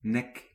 Ääntäminen
IPA: [nɛk]